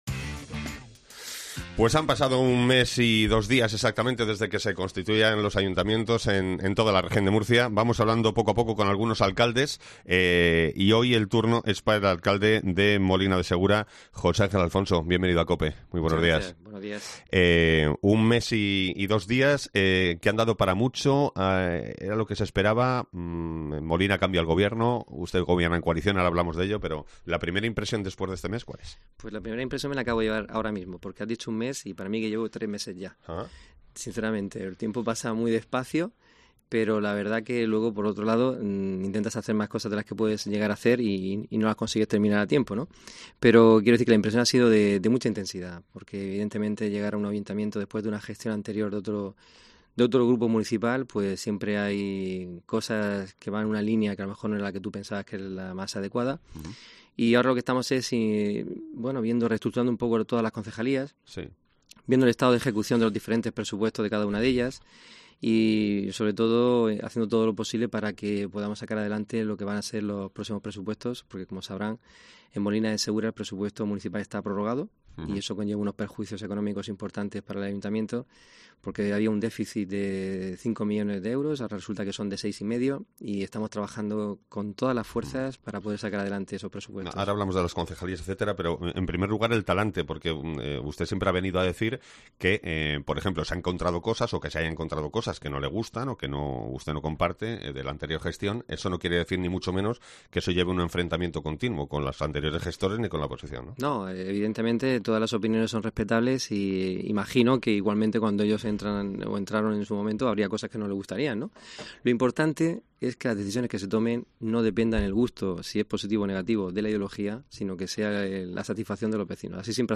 AUDIO: El alcalde de Molina habla de su primer mes al frente del Ayuntamiento